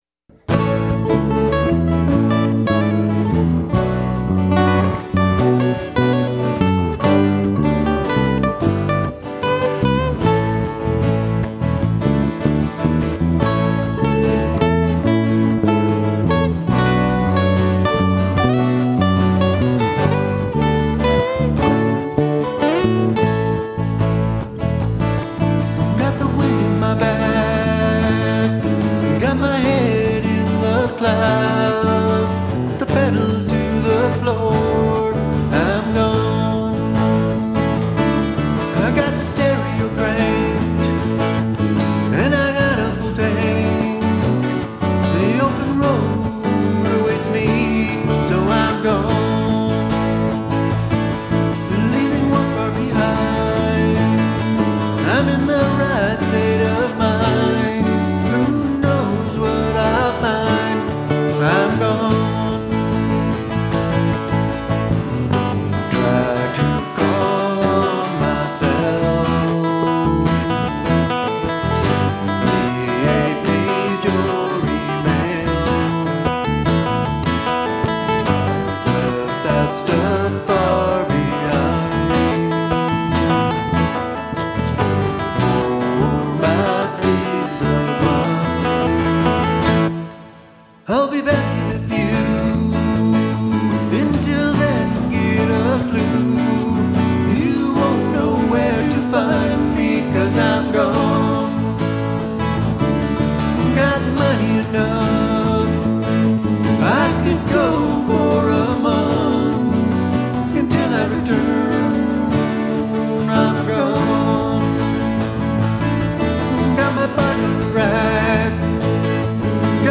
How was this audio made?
It is best to listen to these tunes with headphones as that is how I recorded and mixed them.